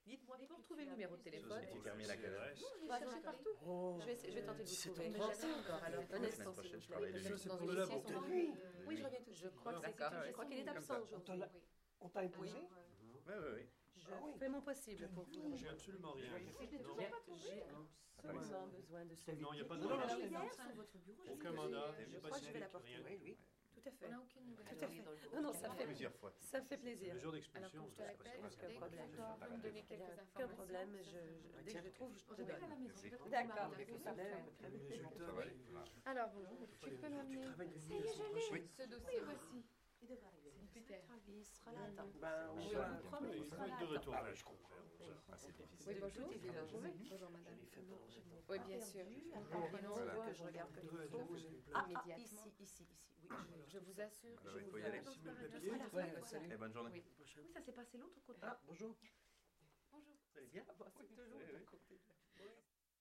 描述：室内声乐（法国）氛围：警察局背景喋喋不休
Tag: 沃拉 定位资产 警察局 声乐氛围 室内